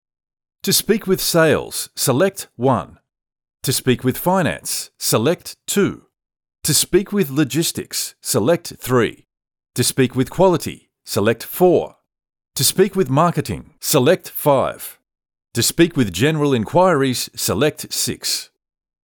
IVR
Rode NT-1, Sennheiser 416 microphones